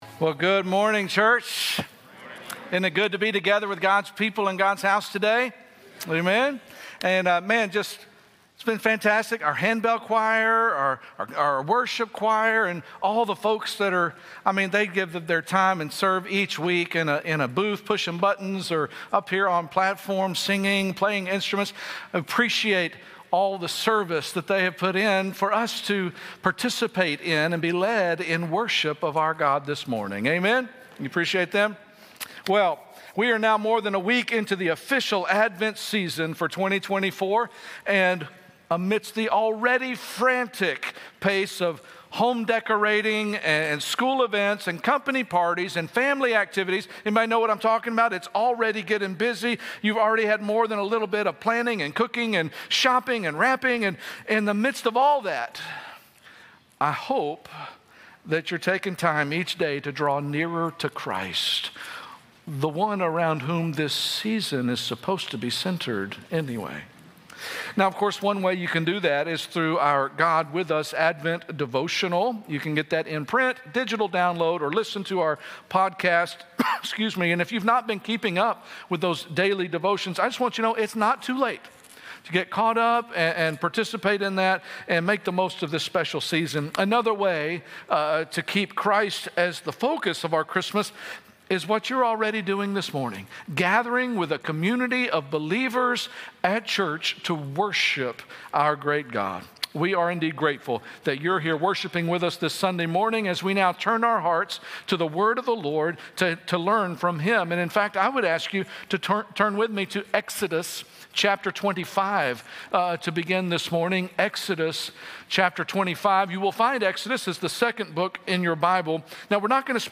Sunday Message 12/8 - God With Us In the Tabernacle